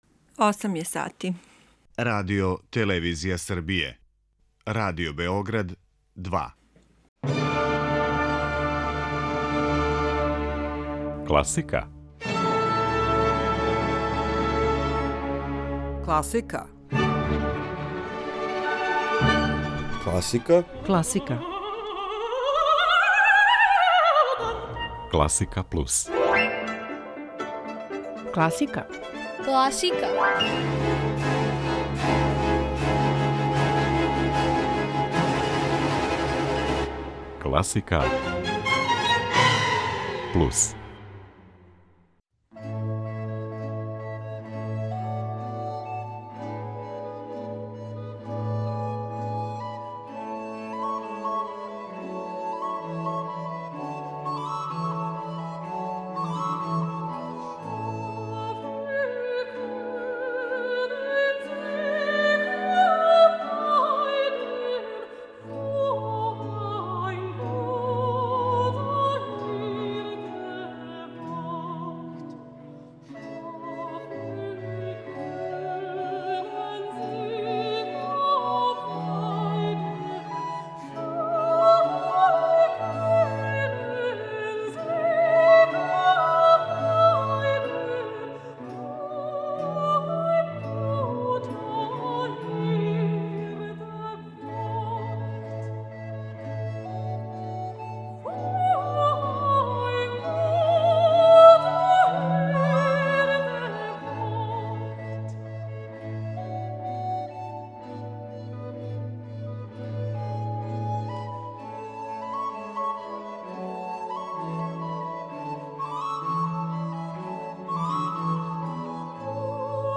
Започните још једно јутро уз класичну музику.